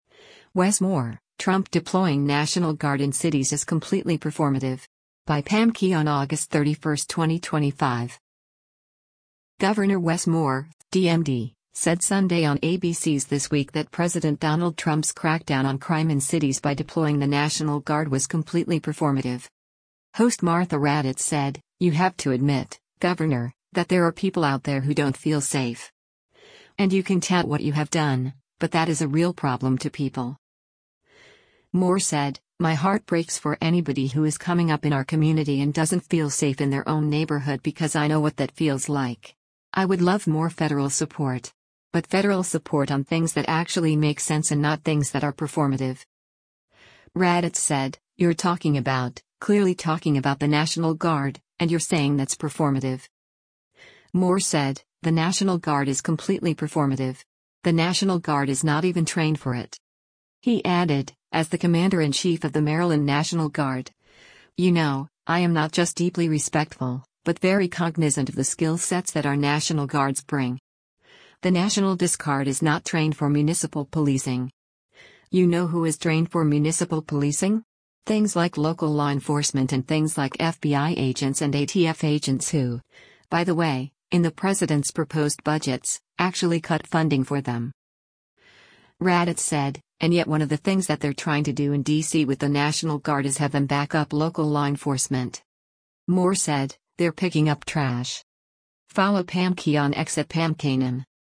Governor Wes Moore (D-MD) said Sunday on ABC’s “This Week” that President Donald Trump’s crackdown on crime in cities by deploying the National Guard was “completely performative.”